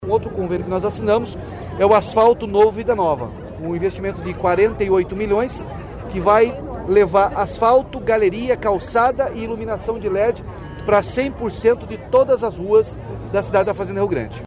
Sonora do governador Ratinho Junior sobre a liberação de 48 de milhões de reais para Fazenda Rio Grande pelo programa Asfalto Novo, Vida Nova